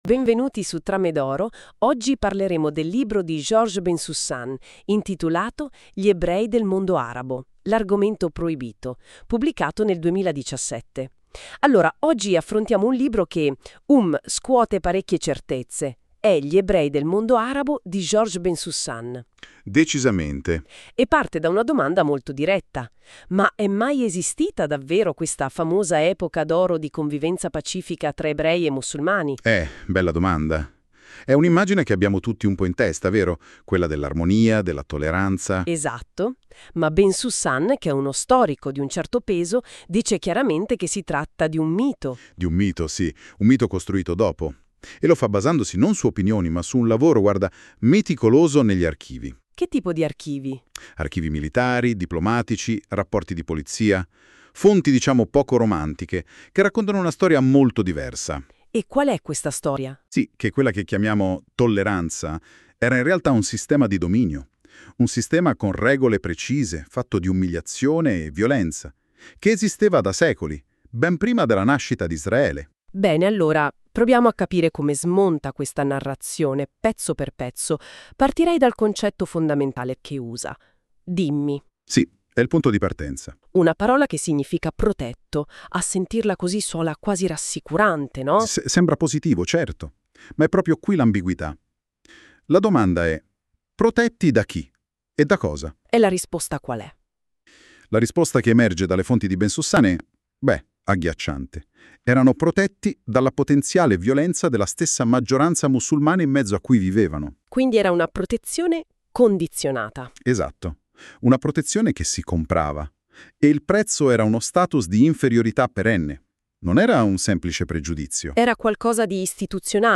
ASCOLTO UNA DISCUSSIONE A DUE VOCI SUL LIBRO